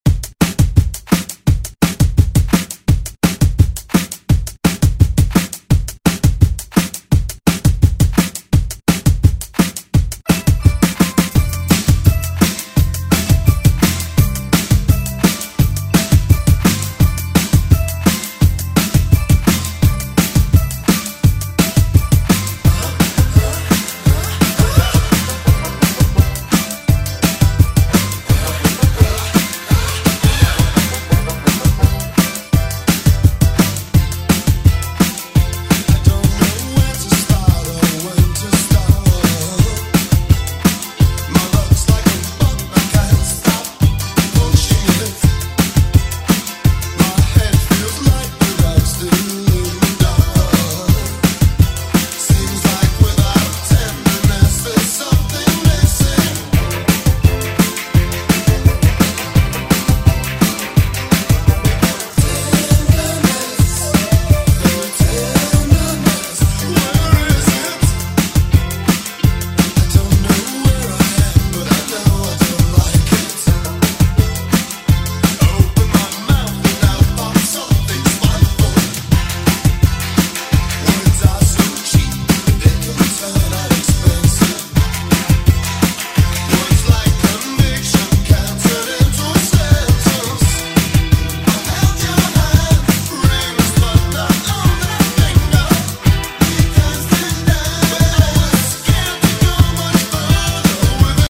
BPM: 170 Time